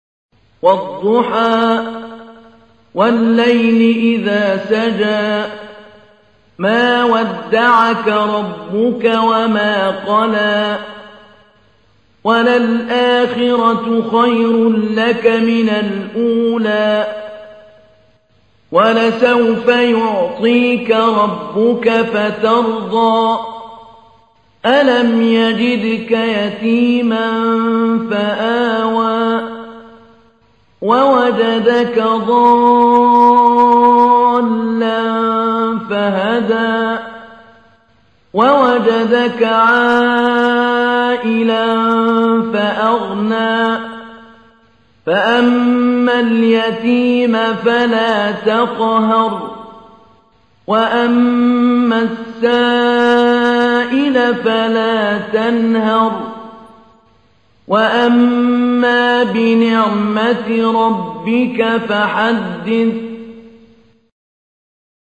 تحميل : 93. سورة الضحى / القارئ محمود علي البنا / القرآن الكريم / موقع يا حسين